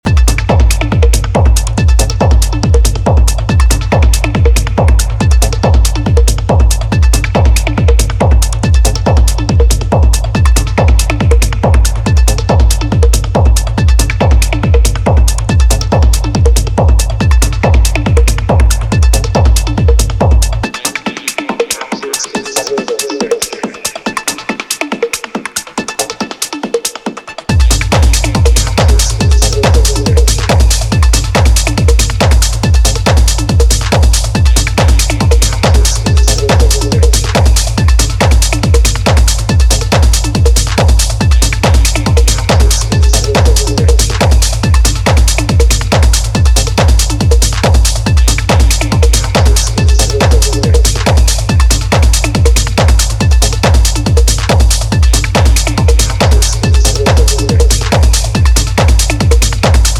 a rich textured track perfect for layering your DJ mixes.